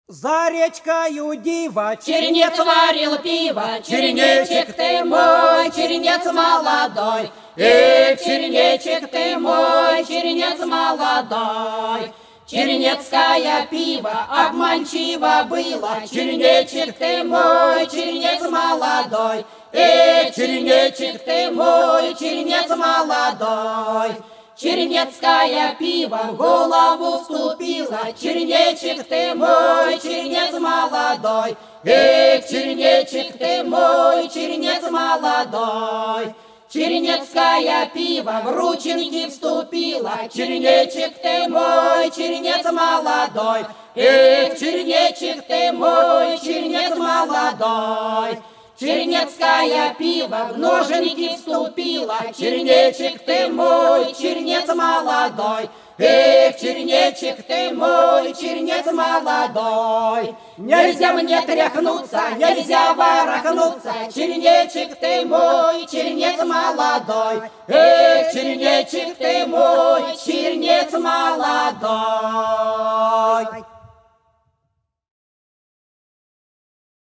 За речкою диво За речкою диво - плясовая (с. Гвазда)